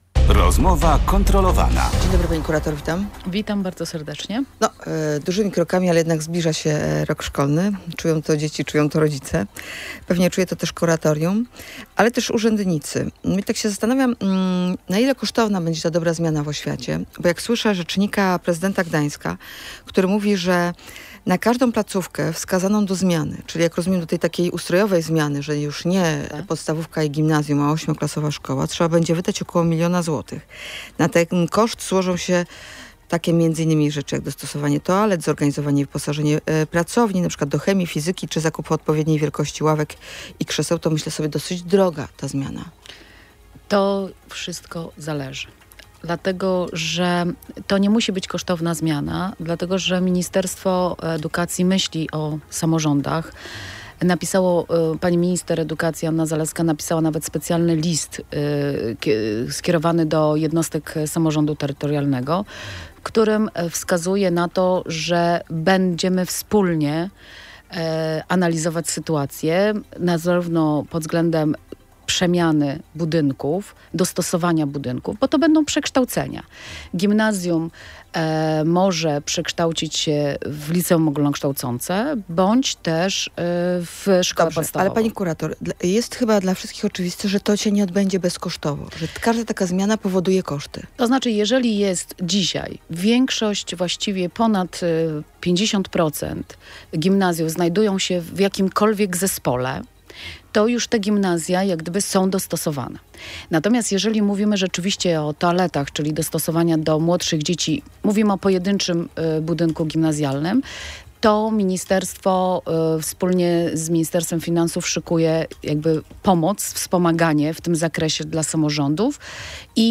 Monika Kończyk w Radiu Gdańsk zapowiedziała też, że kuratorium spotka się ze spółką zarządzającą szkołą i władzami miasta.